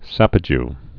(săpə-j)